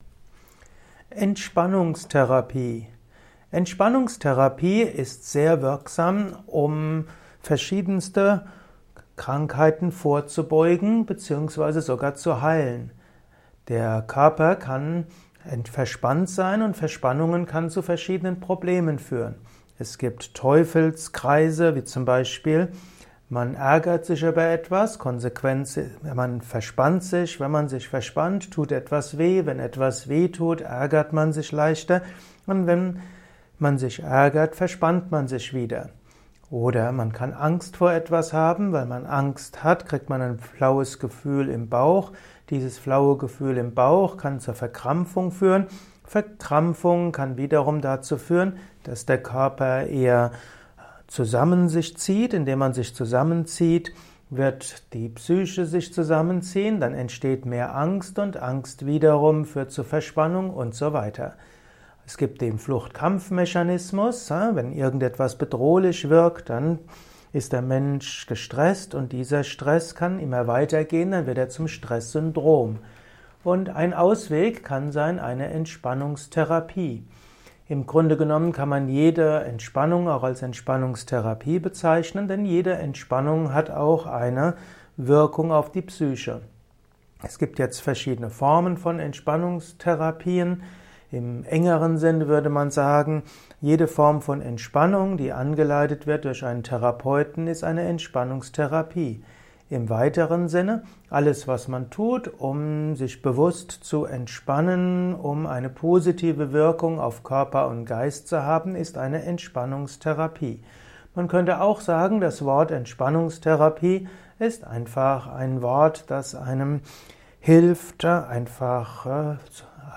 Erfahre einiges zum Thema Entspannungstherapie in diesem kurzen Improvisations-Vortrag.